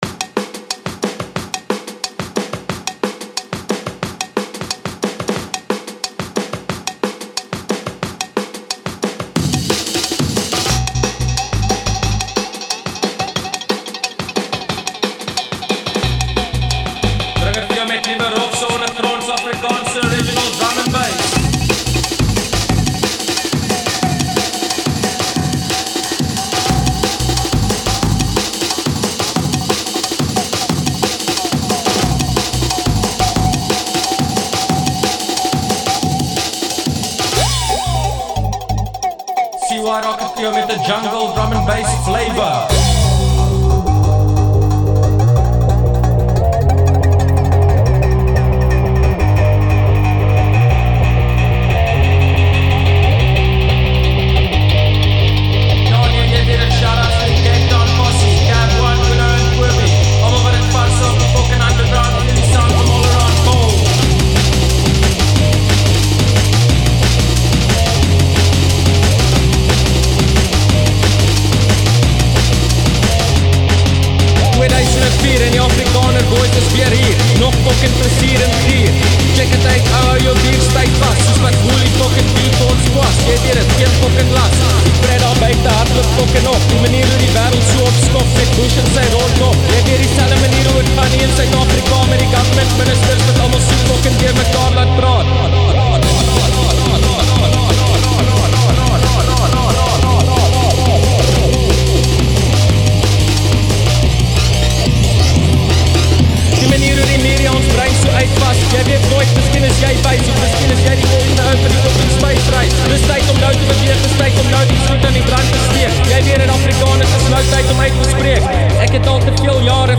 Bellville-based duo